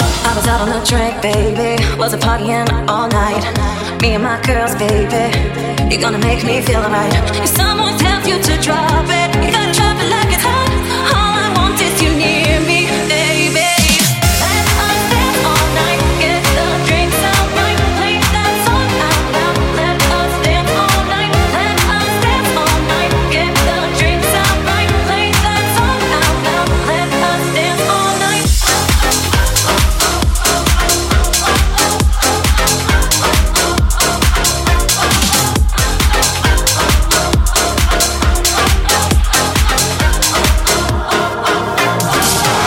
Genere: deep house, tropical, house, club, edm, remix